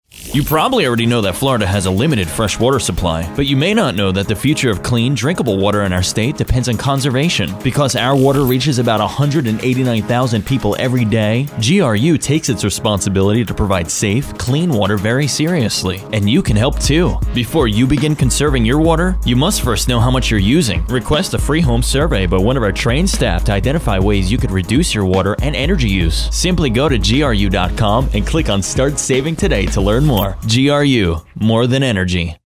Length Radio Spot   Length Radio Spot